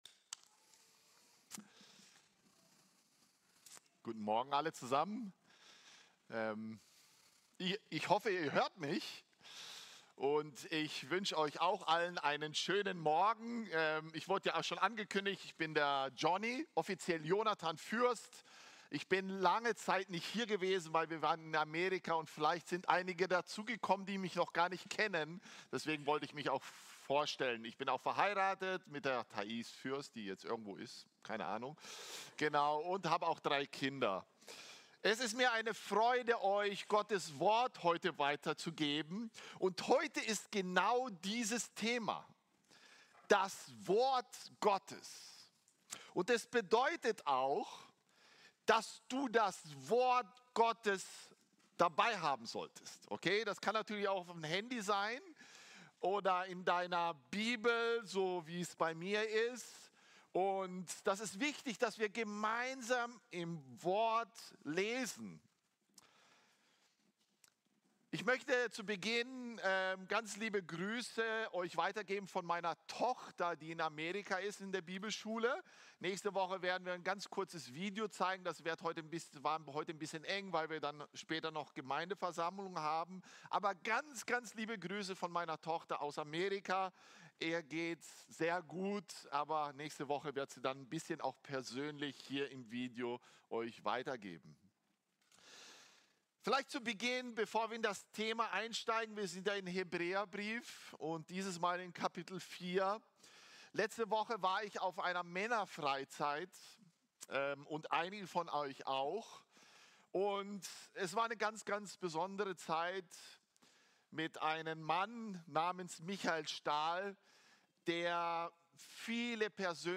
Sermons – Archive – FEG Klagenfurt